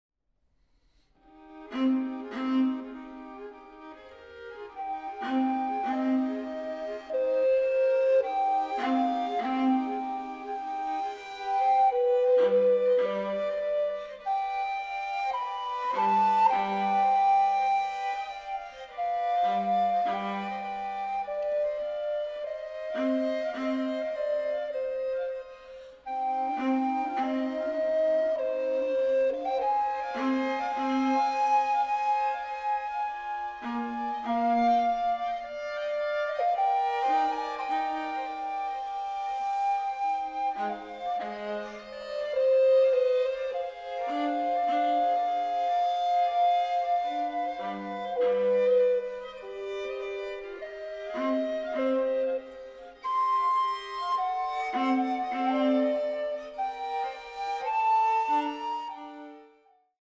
Recorder